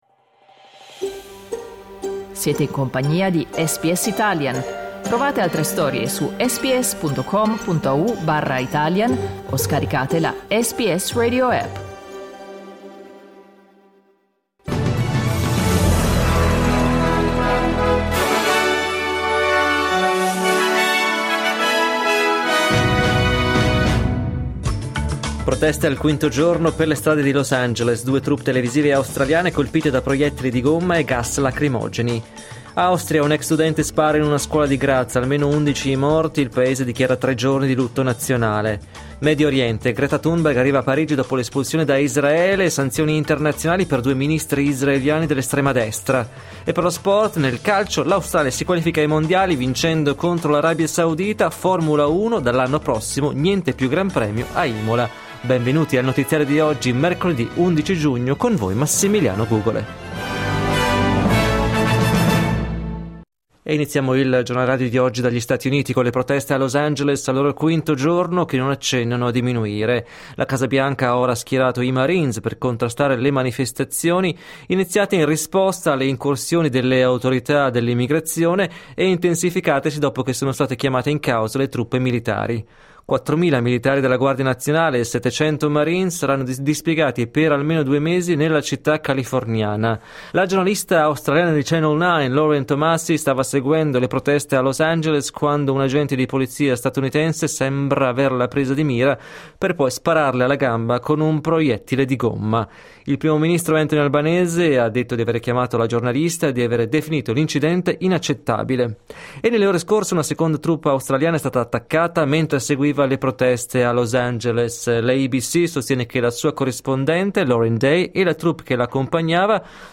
Giornale radio mercoledì 11 giugno 2025
Il notiziario di SBS in italiano.